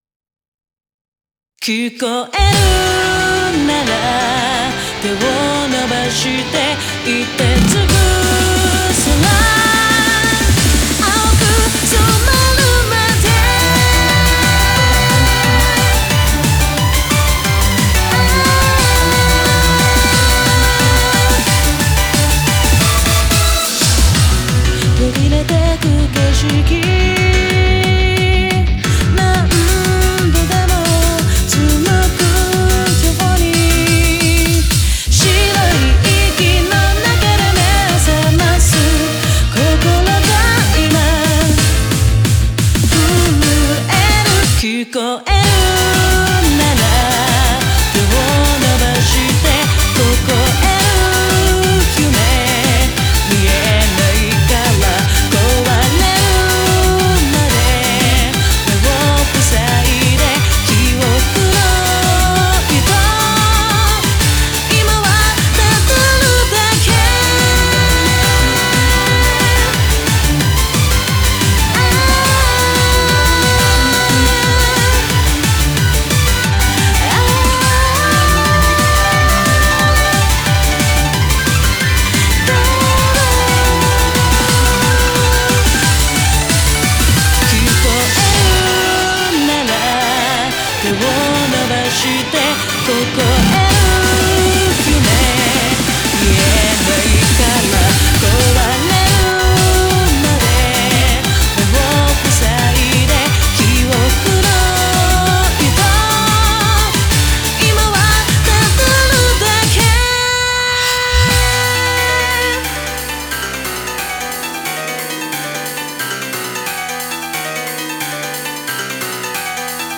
BPM179
Audio QualityMusic Cut